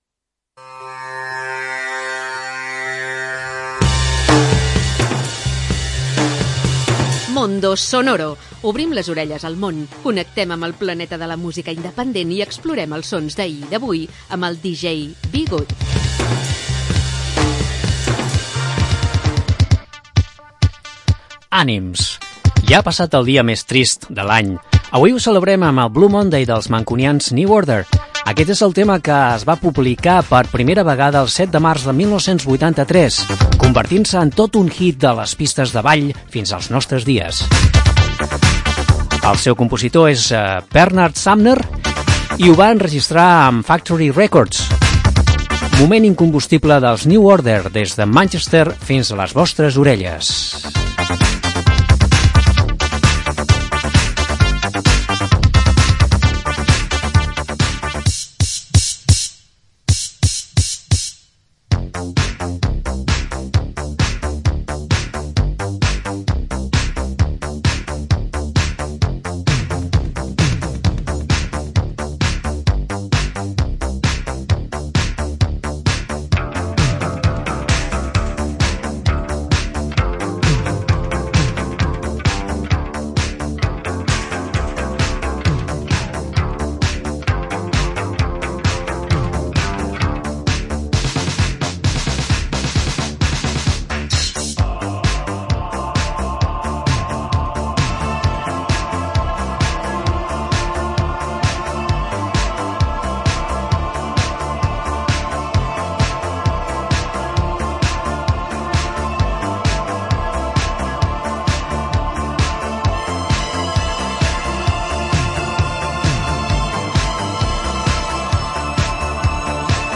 Una selecció de música amb esperit independent